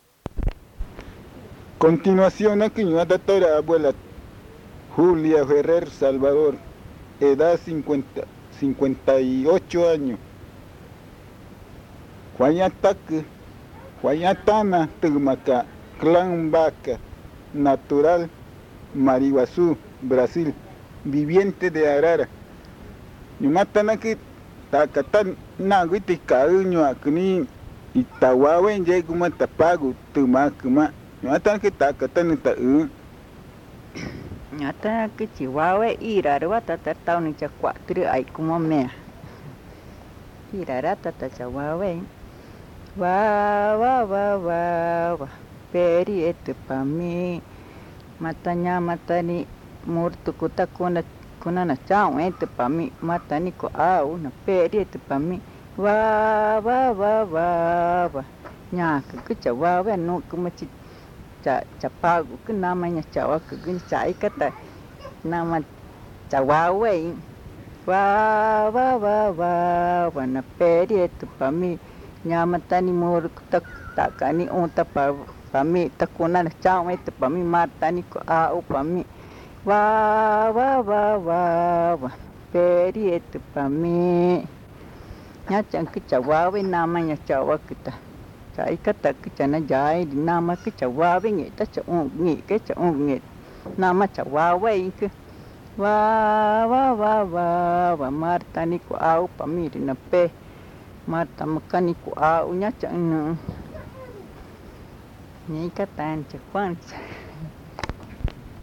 Arrullo con lechuza
Arara, Amazonas (Colombia)
canta un arrullo en Magütá
but then sings a lullaby in Magütá